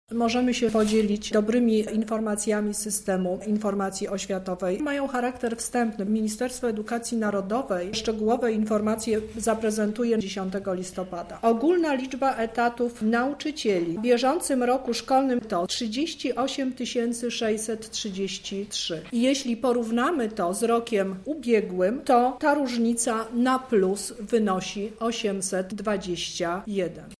Wiadomo między innymi jak niedawno wprowadzona reforma wpłynęła na zatrudnienie nauczycieli. O tym jak wygląda ich sytuacja mówi Lubelski Kurator Oświaty Teresa Misiuk: